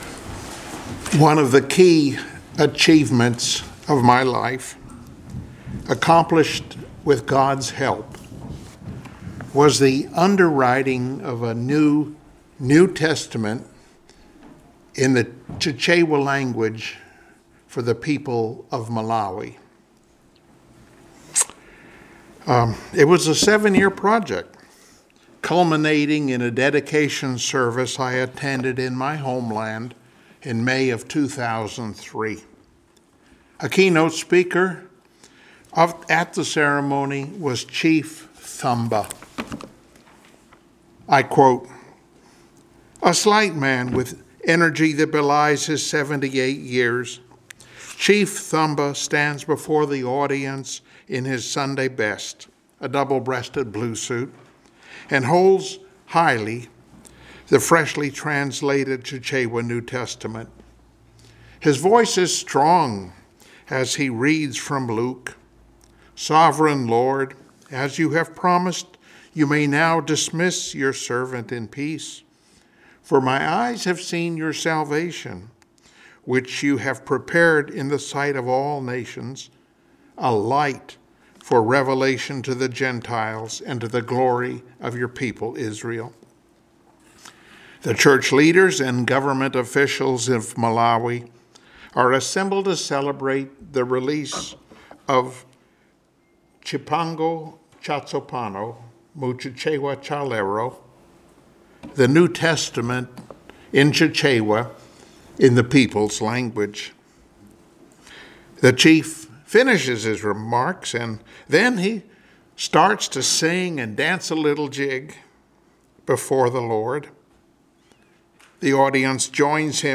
Luke 2:25-32 Service Type: Sunday Morning Worship Topics: "Looking" , Return of Christ , Simeon's faith « “Crown